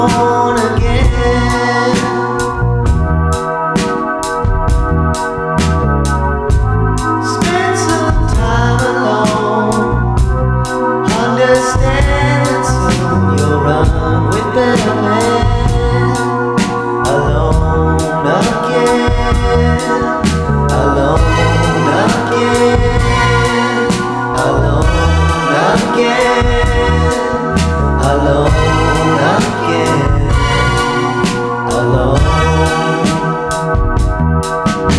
Жанр: Иностранный рок / Рок / Инди / Альтернатива